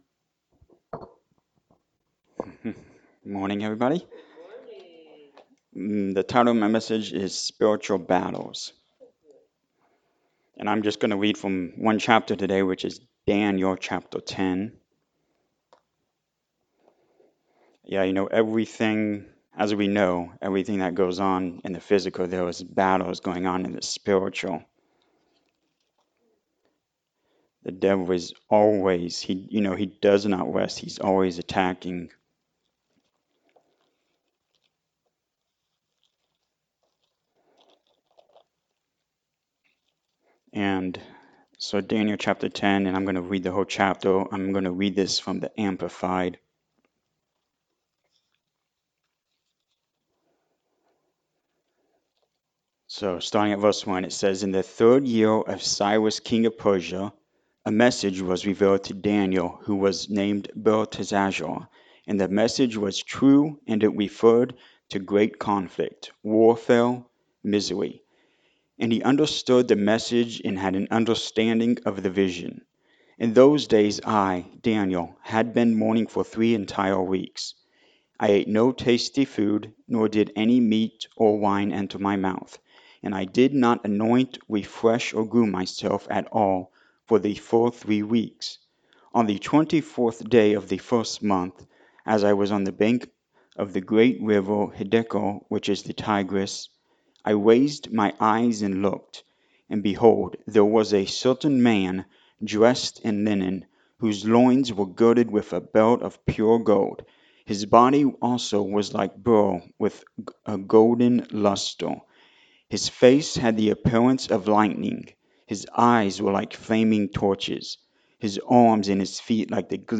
Sunday-Sermon-for-October-1-2023.mp3